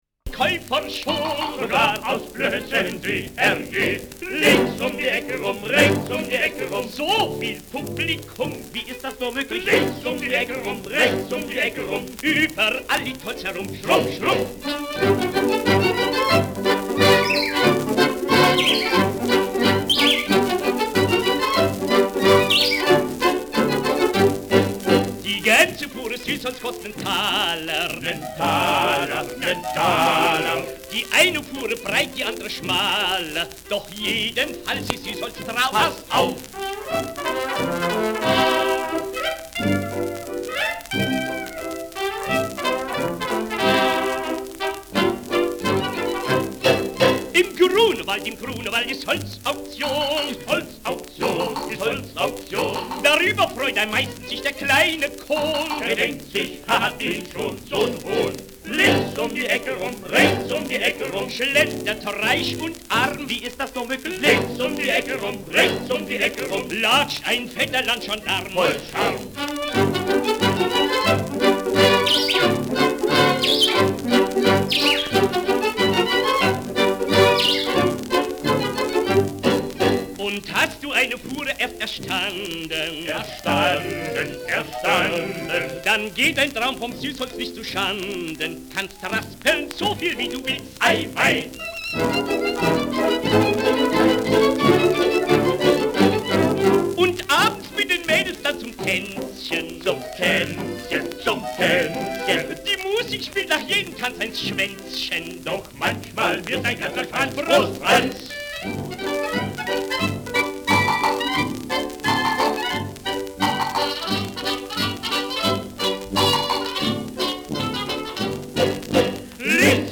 Rheinländer
Schellackplatte
Tonrille: Bruch 8-10 Uhr : Kratzer 3 / 11 Uhr Leicht
Teil der Platte ausgebrochen, von dort digitalisiert